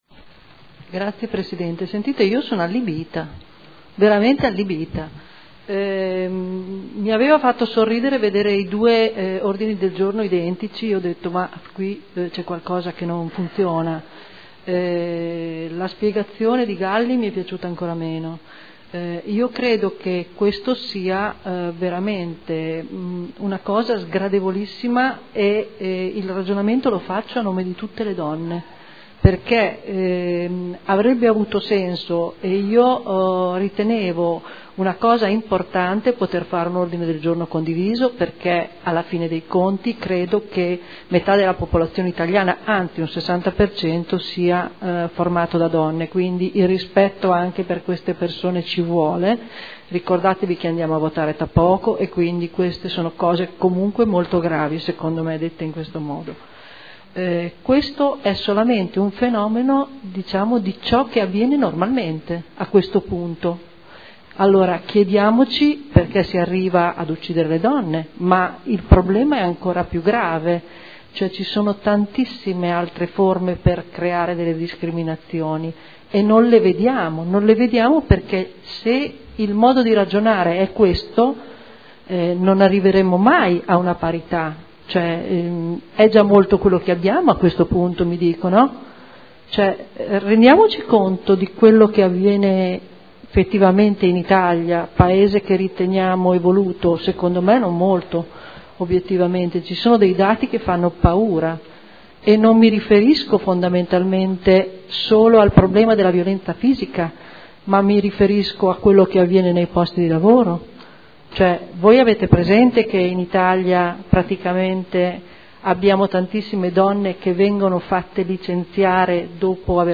Marcella Nordi — Sito Audio Consiglio Comunale
Seduta del 27/03/2014. Dibattito su Ordine del Giorno presentato dal gruppo consiliare SEL per condannare ogni espressione sessista e ogni forma di discriminazione di genere usata come arma di battaglia politica e per respingere il clima di incitamento all’odio sessista che ha colpito tutte le donne presenti nelle istituzioni, e Ordine del Giorno presentato dai consiglieri Galli, Taddei, Morandi, Bellei (Forza Italia – PdL), Celloni (Movimento per Cambiare – Insieme per Modena), avente per oggetto: “Condannare ogni espressione sessista e ogni forma di discriminazione di genere usata come arma di battaglia politica e per respingere il clima di incitamento all’odio sessista che ha colpito tutte le donne presenti nelle istituzioni”